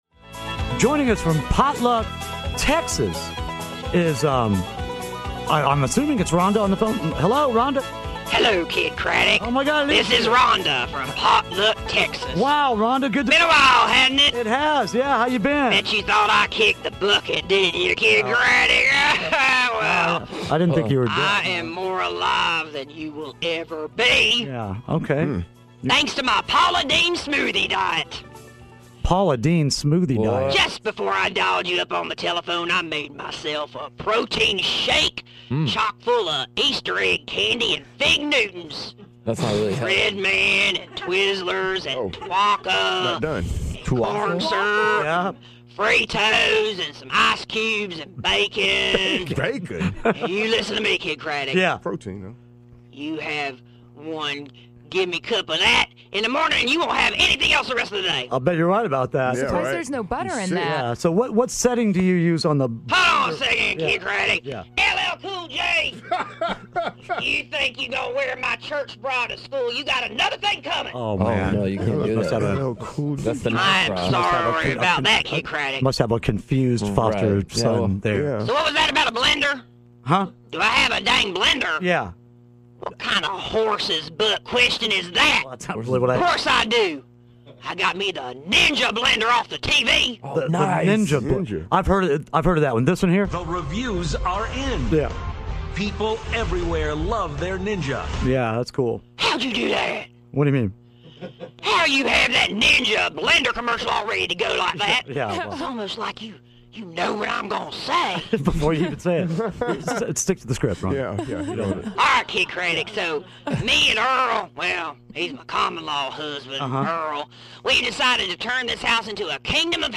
Rhonda, from Potluck, Texas, calls in to talk about her new quest to get thin and how her Paula Dean smoothies are helping her get in shape!